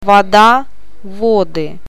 O ääntyy painottomana a :n kaltaisena, e ja я i :n tai ji :n tapaisena äänteenä.
Painon paikka voi myös vaihdella sanan eri sijamuodoissa: